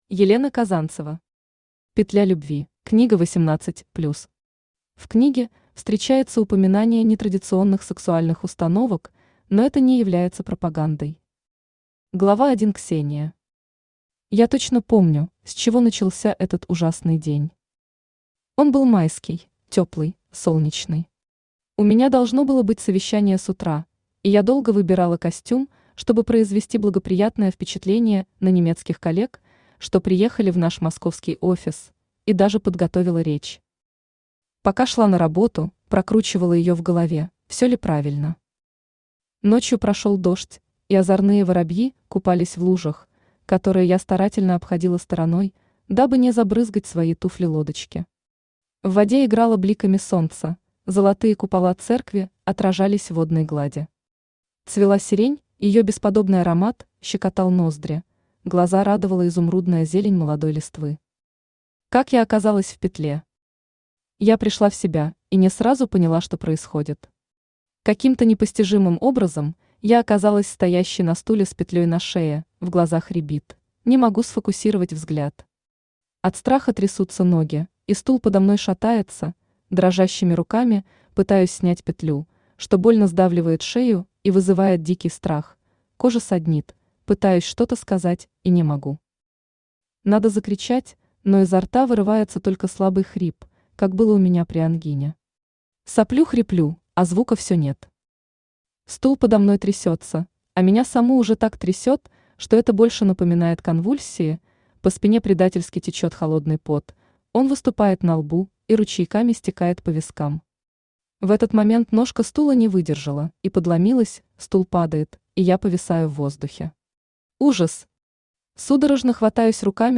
Аудиокнига Петля любви | Библиотека аудиокниг
Aудиокнига Петля любви Автор Елена Казанцева Читает аудиокнигу Авточтец ЛитРес.